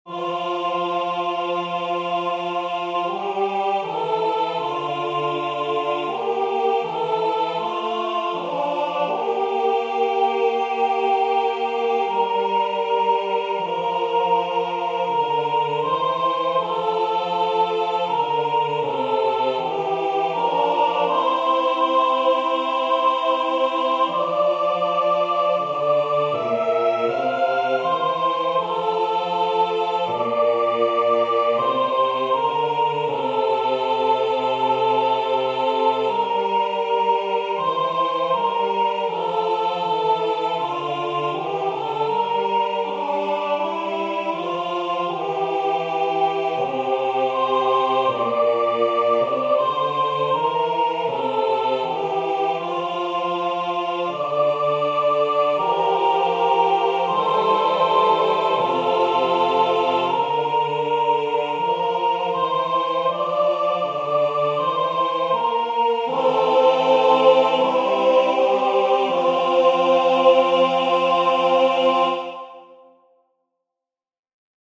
Benediction Choral - SATB